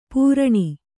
♪ pūraṇi